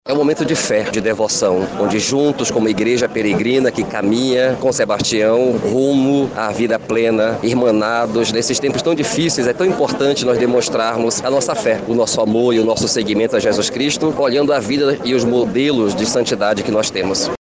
SONORA-1-PROCISSAO-SAO-SEBASTIAO-2.mp3